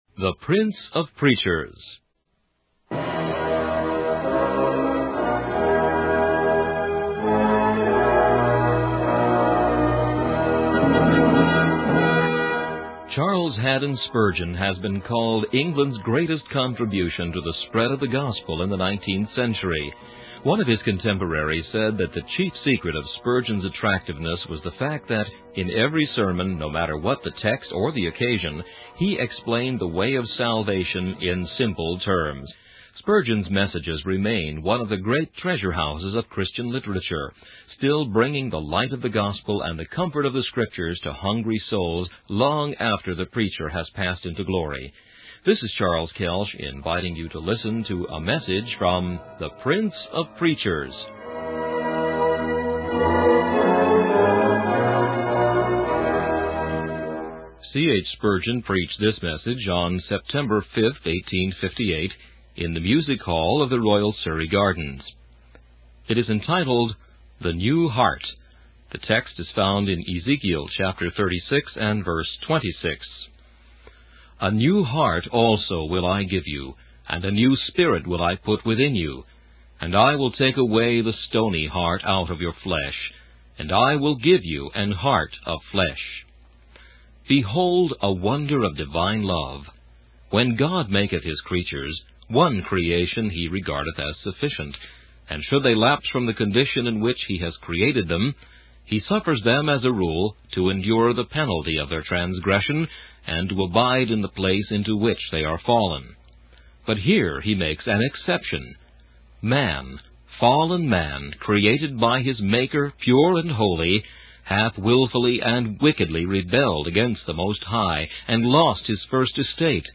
In this sermon, the preacher emphasizes the necessity of the great promise of God.